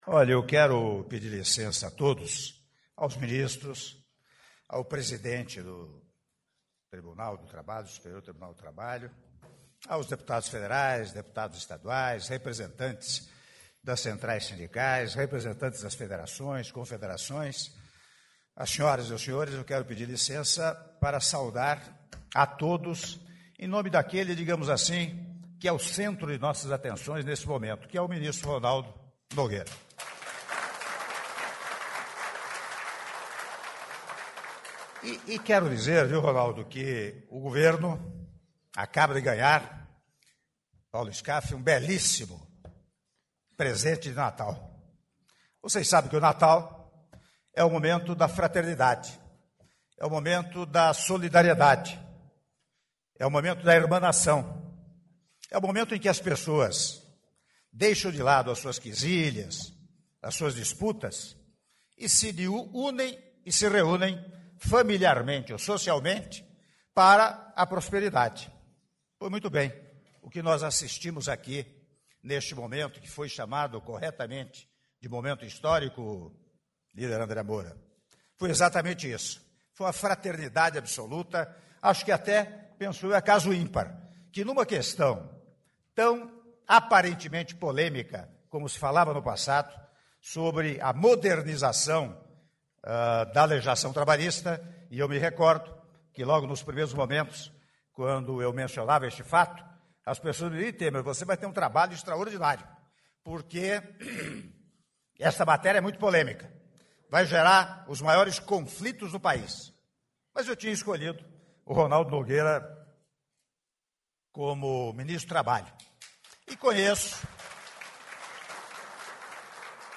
Áudio do Presidente da República, Michel Temer, durante cerimônia de assinatura de MP com Medidas do Programa de Manutenção e Geração de Empregos - Palácio do Planalto/DF - (06min20s)